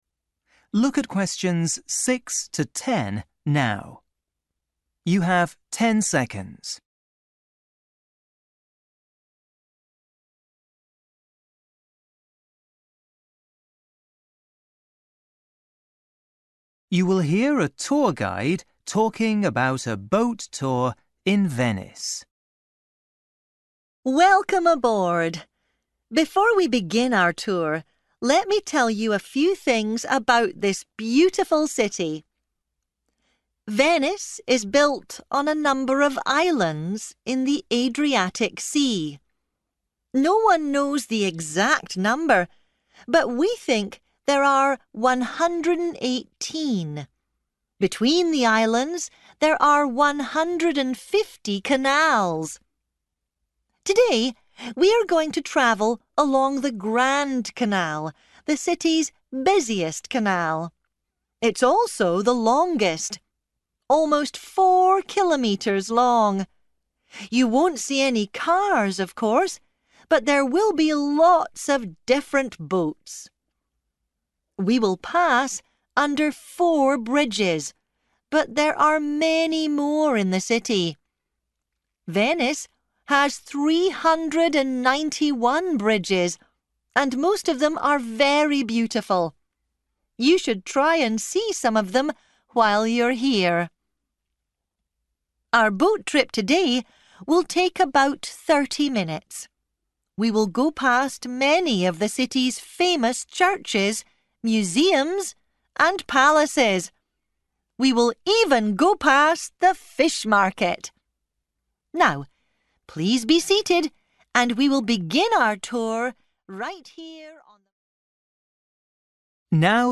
You will hear a tour guide talking about a boat tour in Venice.